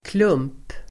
Uttal: [klum:p]